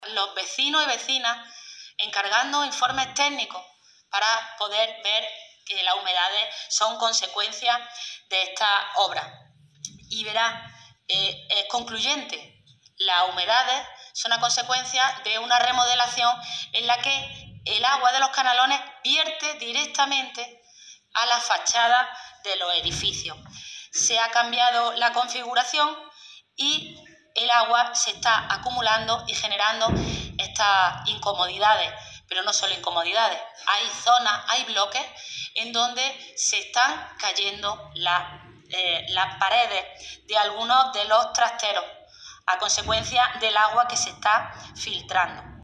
En Comisión de Fomento, Articulación del Territorio y Vivienda
Cortes de sonido